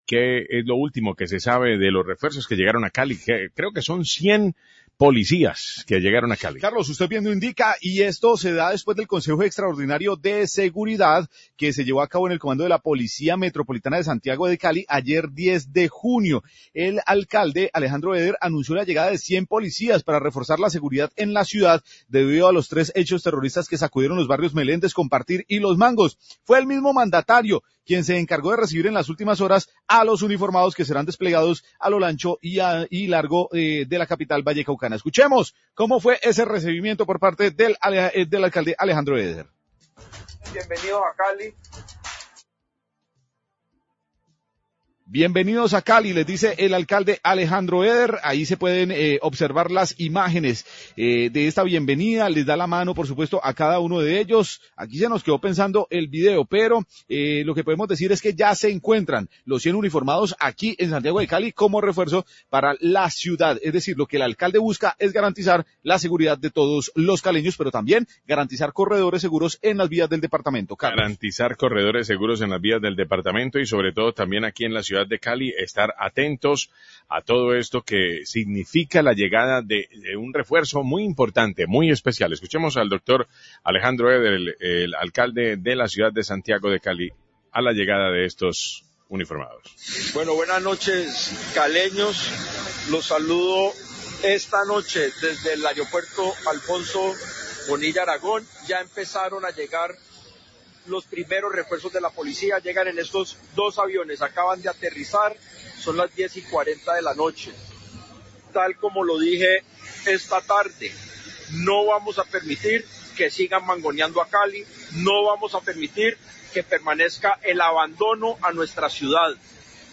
Alcalde habla de llegada de policías a la ciudad, 825am
Radio